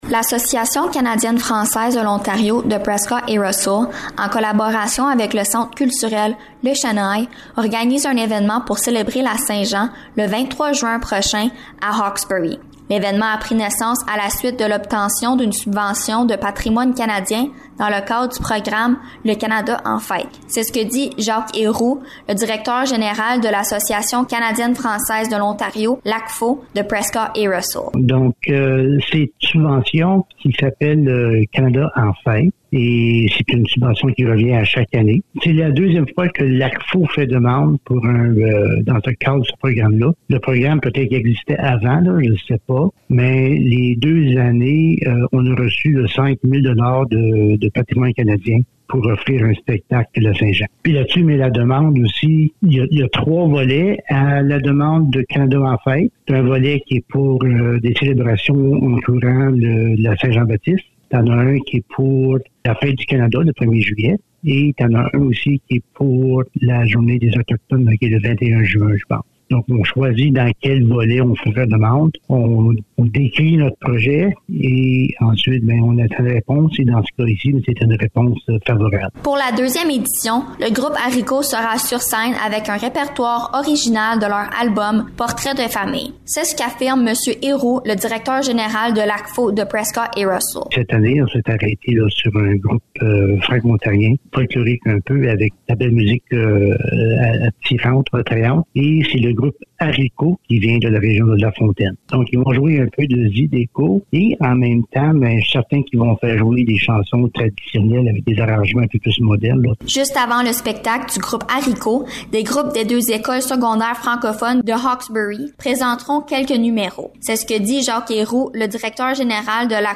Reportage-St-Jean.mp3